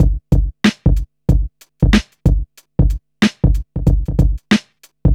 • 93 Bpm Drum Loop Sample G Key.wav
Free drum beat - kick tuned to the G note.
93-bpm-drum-loop-sample-g-key-Zsu.wav